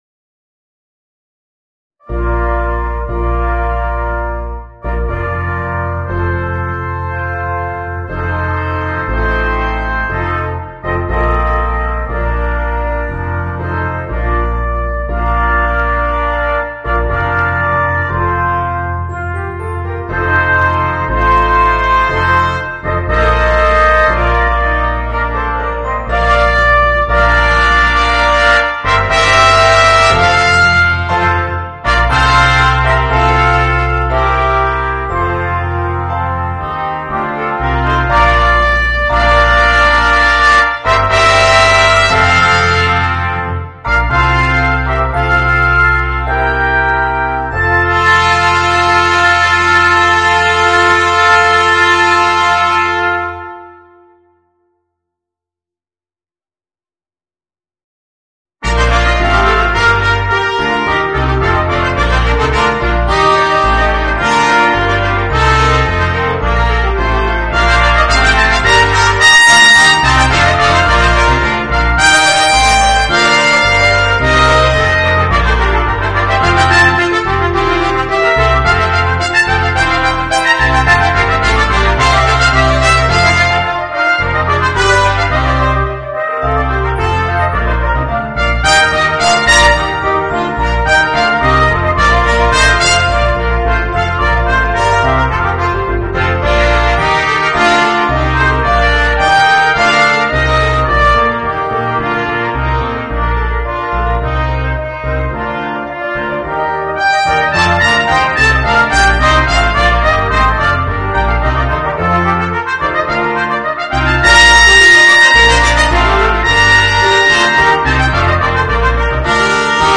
Voicing: 5 Trumpets